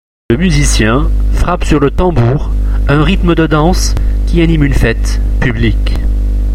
L’IMAGE DU JOUR – Fête dans la rue
Podcast_Fete_Rue.mp3 (104.91 Ko)
Le musicien frappe sur le tambour le rythme de la danse qui anime une fête publique.